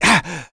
Zafir-Vox_Damage_02.wav